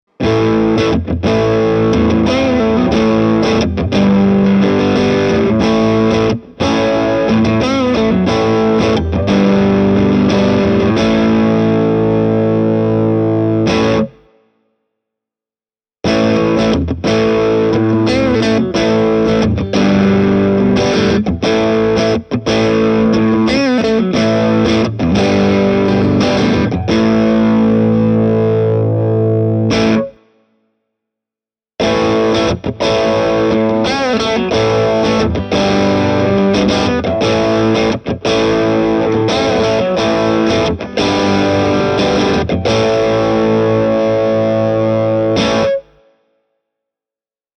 Kaikki ääninäytteet on äänitetty dynaamisella mikrofonilla. Kaiku on lisätty miksausvaiheessa:
Fender Telecaster – säröllä
fender-telecaster-e28093-crunch.mp3